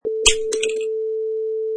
Sound effects: Mouse Trap 1
Mouse trap pops
Product Info: 48k 24bit Stereo
Category: Machinery / Mousetraps
Try preview above (pink tone added for copyright).
Tags: smack
Mouse_Trap_1.mp3